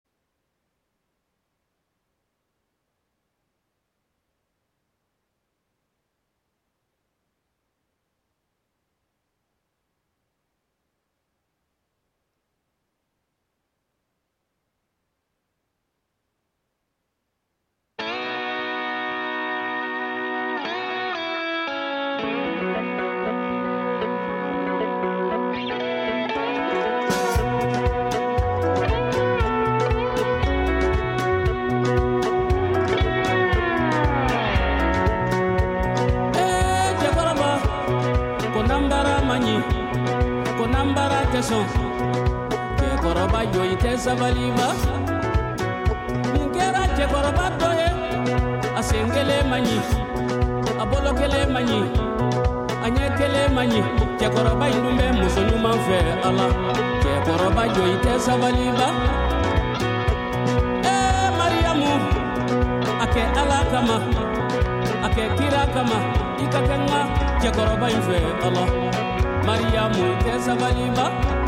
Broadcasting live from Catskill, NY.